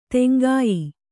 ♪ teŋgāy